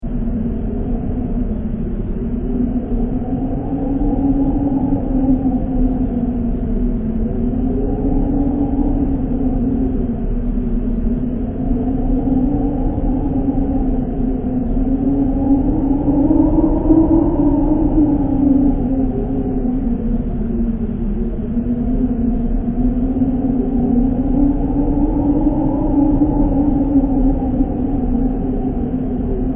mrairflow1.mp3